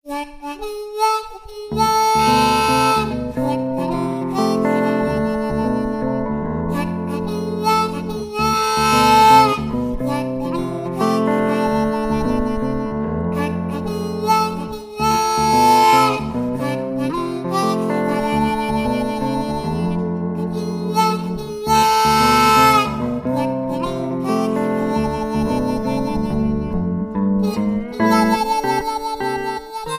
Lap Slide Steel Guitar
Harmonica
A lap slide guitar and a harmonica.
an instrumental blues duet